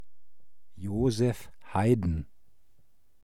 Franz Joseph Haydn (pronunciado
De-Joseph_Haydn.oga.mp3